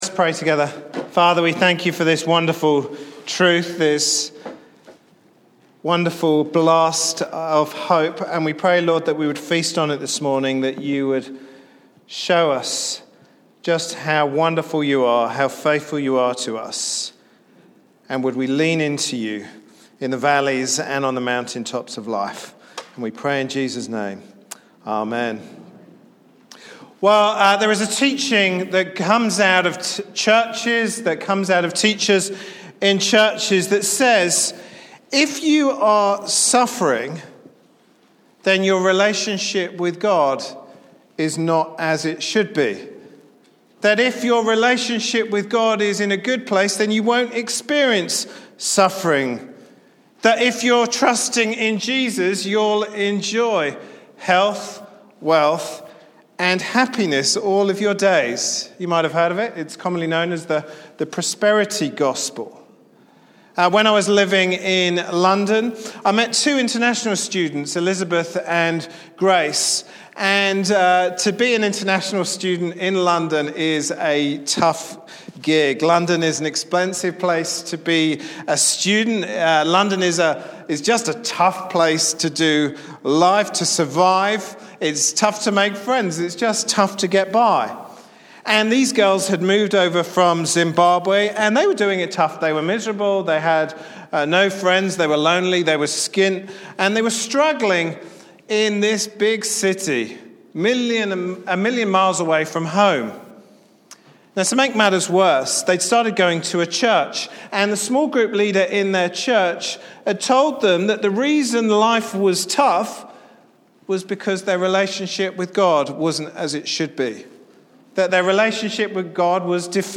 Passage: 1 Peter 1:1-9 Service Type: Sunday morning service